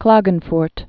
(klägən-frt)